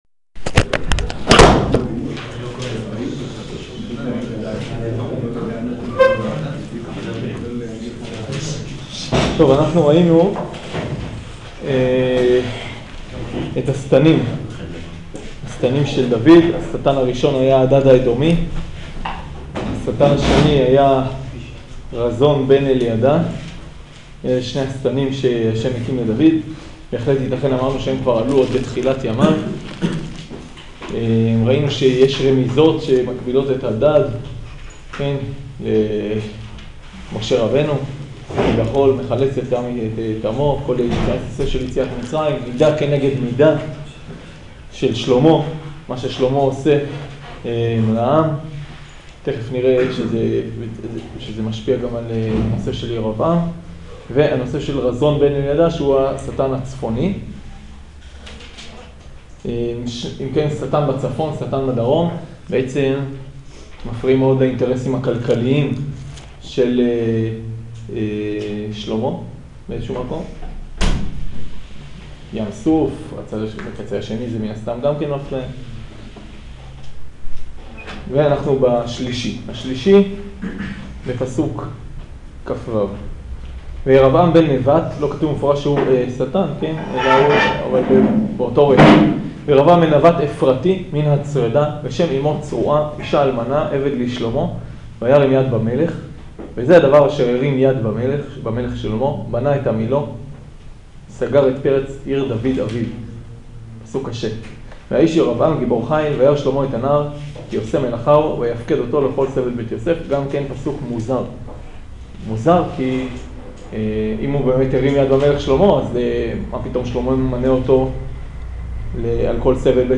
שיעור פרק יא